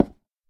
Sound / Minecraft / dig / wood1.ogg
wood1.ogg